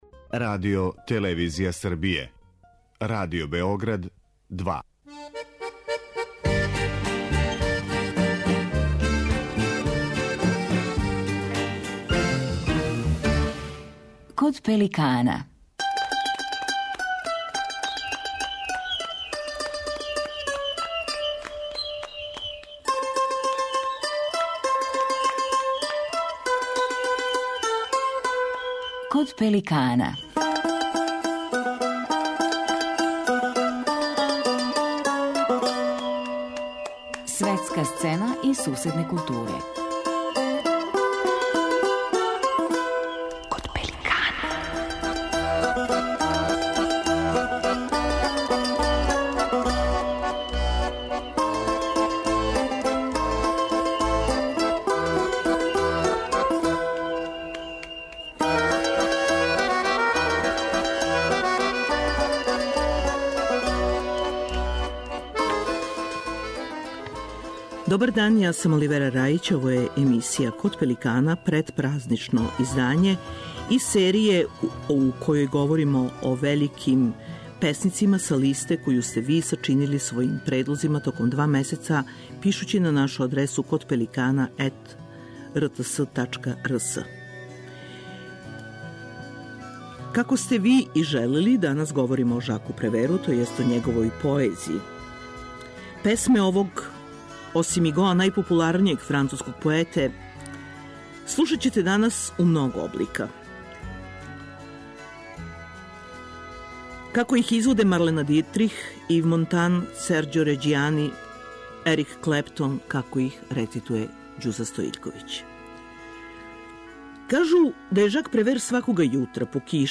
У серији посвећеној поезији и песницима које су гласањем изабрали сами слушаоци ово, претпразничко издање, посвећујемо поезији Жака Превера и славним, музичким и говорним, интерпретацијама његових песама. Две од тих песама говори сам Жак Превер.